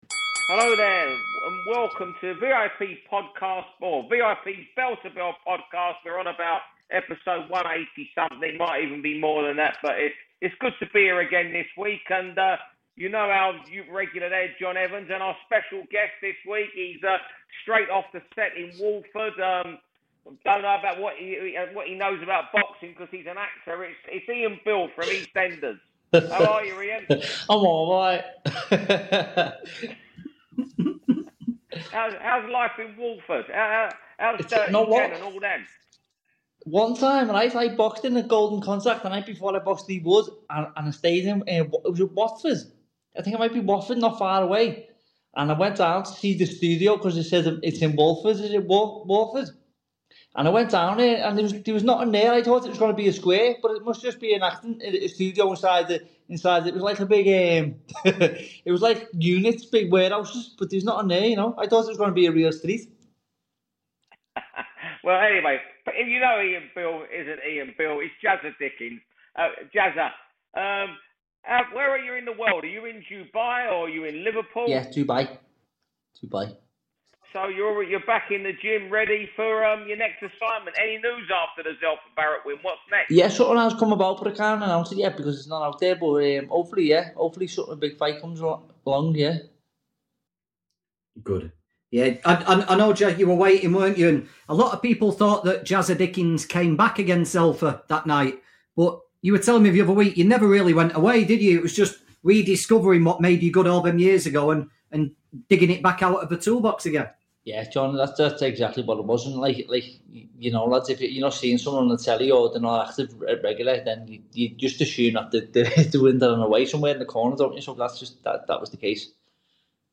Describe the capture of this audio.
he's away from his usual recording setup, but we didn’t want to miss the chance to bring you the pod.